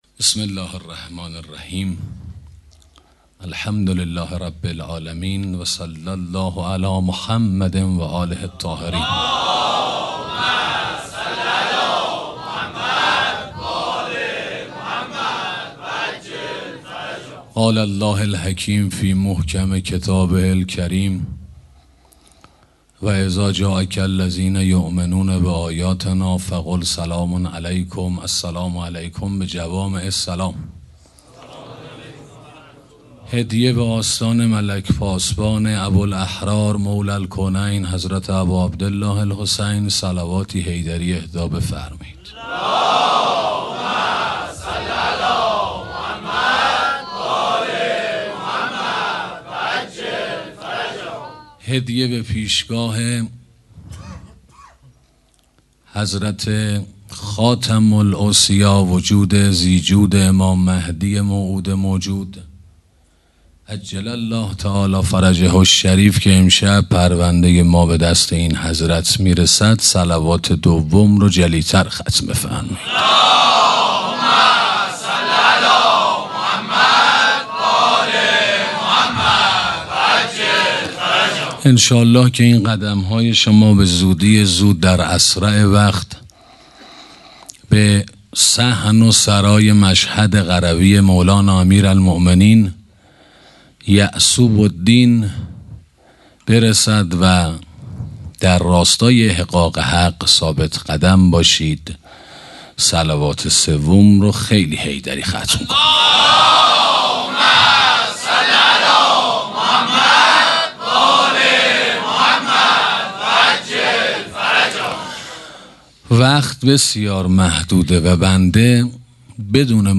سخنرانی مرگ و قبر 1 - موسسه مودت